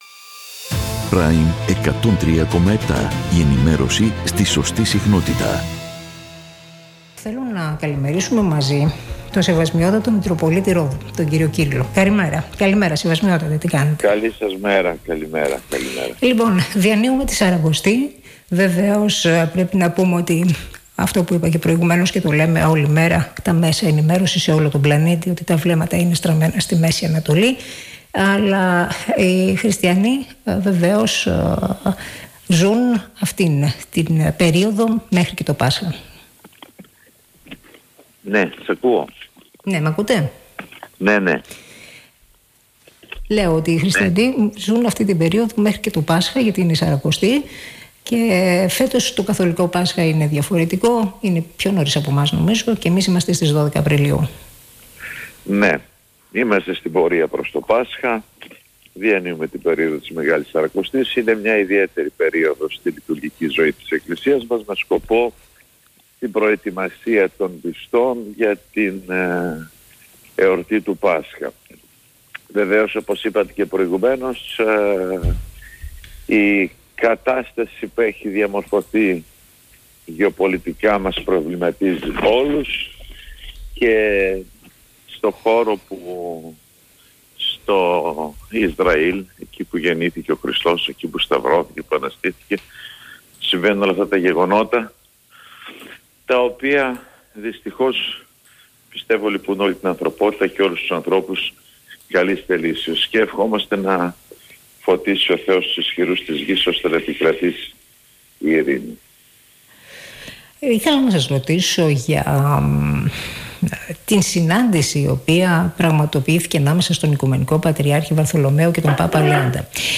Την σημασία της περιόδου της Μεγάλης Σαρακοστής μέχρι τις άγιες μέρες του Πάσχα, επισήμανε μιλώντας στον Ρ/Σ Prime 103,7, ο Σεβασμιότατος Μητροπολίτης Ρόδου κ. Κύριλλος.